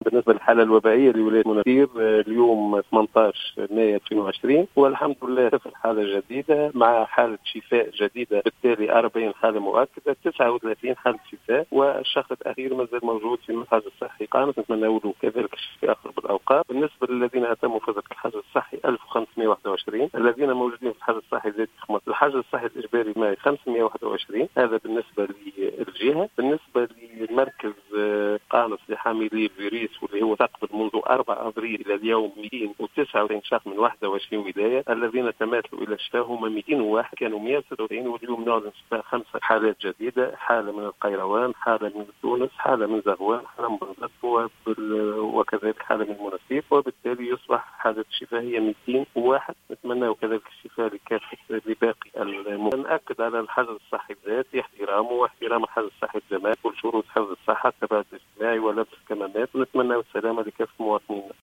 قال المدير الجهوي للصحة بالمنستير، المنصف الهواني في تصريح اليوم لـ"الجوهرة أف أم" إنه لم يتم تسجيل اي إصابات جديدة بفيروس "كورونا" في الجهة.